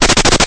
Run.ogg